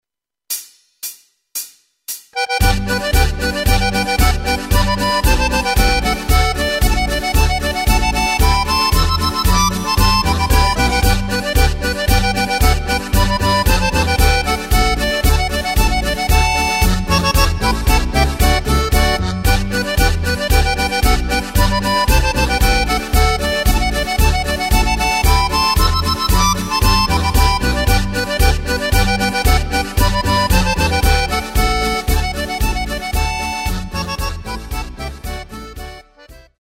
Takt:          2/4
Tempo:         114.00
Tonart:            G
Schweizer Ländler/Polka!